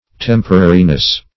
Temporariness \Tem"po*ra*ri*ness\, n.
temporariness.mp3